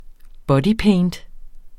Udtale [ ˈbʌdiˌpεjnd ]